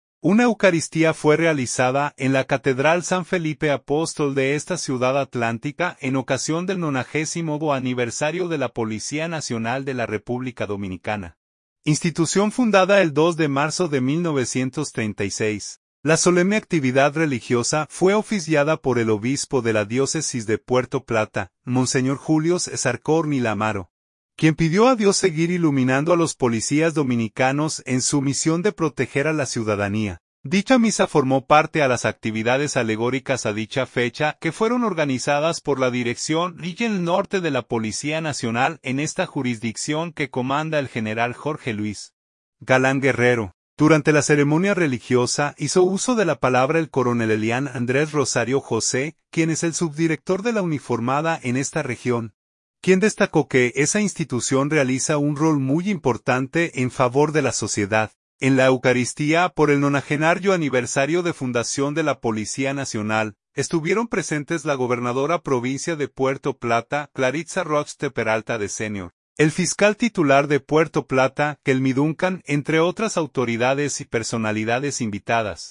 PUERTO PLATA.- Una eucaristía fue realizada en la catedral San Felipe Apóstol de esta ciudad atlántica en ocasión del 90.º aniversario de la Policía Nacional de la República Dominicana, institución fundada el 2 de marzo de 1936.
La solemne actividad religiosa fue oficiada por el obispo de la diócesis de Puerto Plata, monseñor Julio César Corniel Amaro, quien pidió a Dios seguir iluminando a los policías dominicanos en su misión de proteger a la ciudadanía.